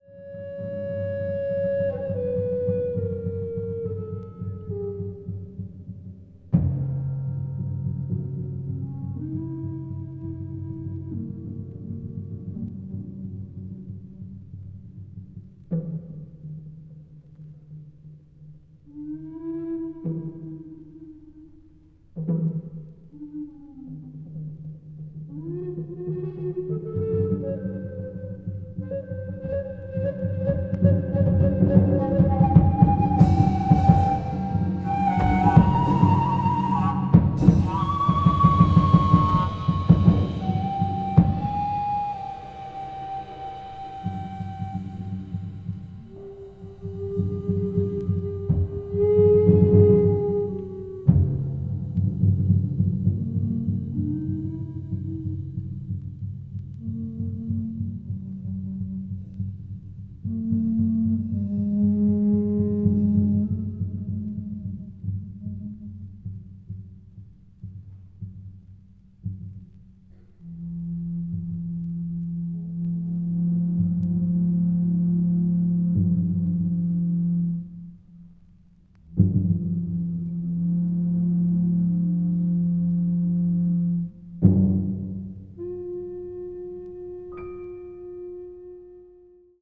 media : EX/EX,EX/EX(わずかにチリノイズが入る箇所あり)